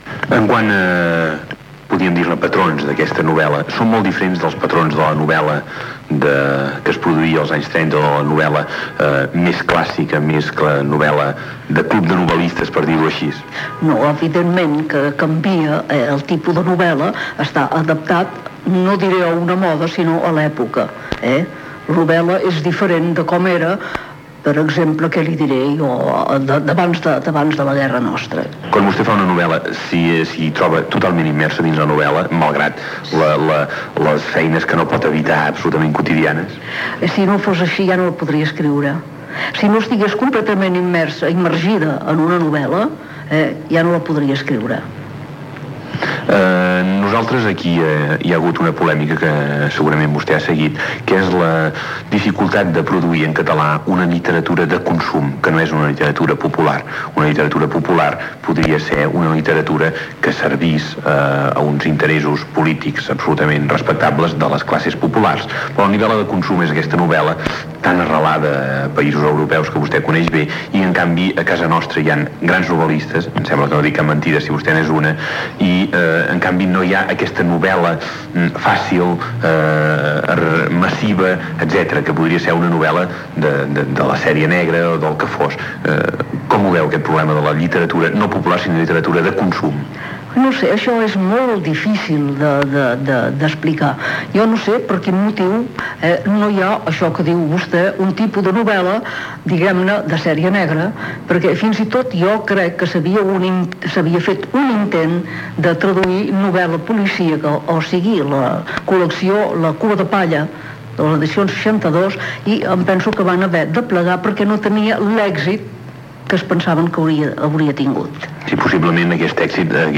Entrevista a l'escriptora Mercè Rodoreda per la publicació del seu llibre "Mirall trencat"
Informatiu